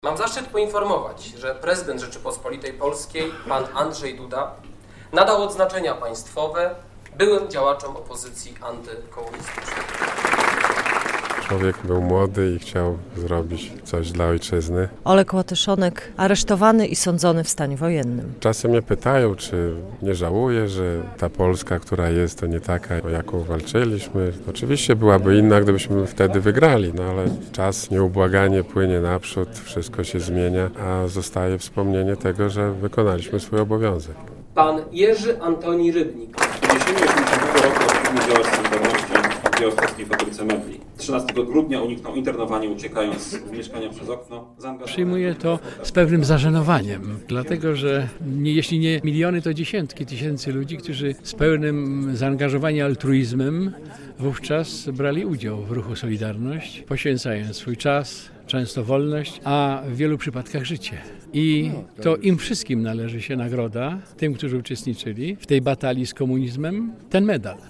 Odznaczenia dla działaczy "Solidarności" - relacja
Na uroczystości w białostockiej siedzibie IPN Prezes Instytutu Łukasz Kamiński wyrażał wdzięczność odznaczonym, dzięki którym "dziś możemy się cieszyć naszą wolnością":